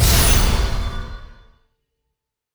sfx_gacha 02.wav